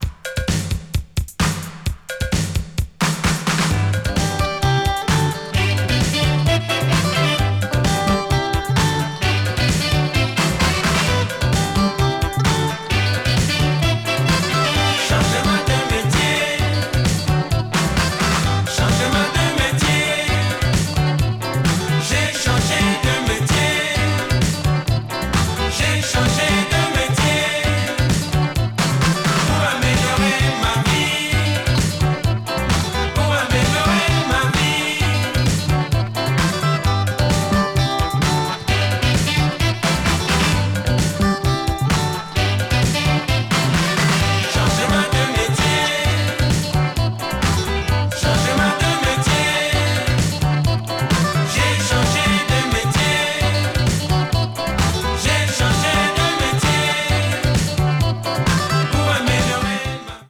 打ち込みドラムを導入したモダンで都会的なアレンジの80'sスークース～アフロ・ポップを展開しています！